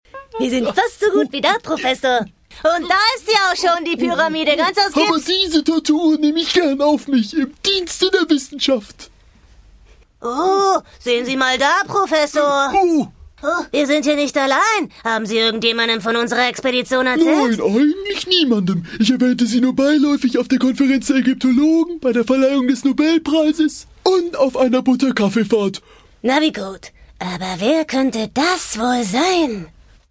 Sprechprobe: Industrie (Muttersprache):
german female voice over artist